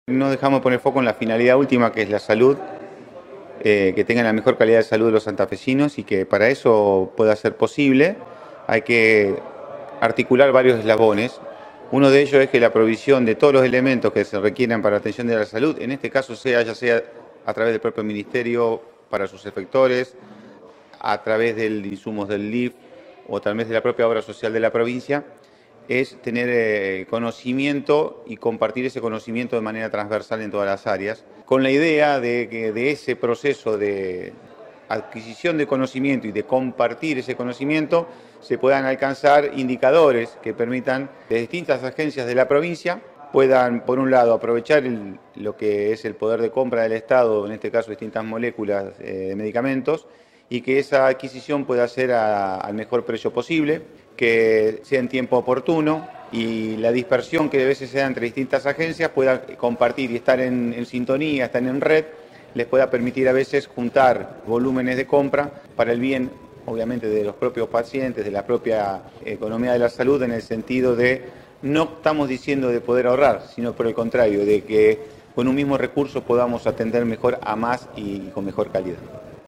PABLO-OLIVARES-Ministro-de-Economia-de-Santa-Fe.mp3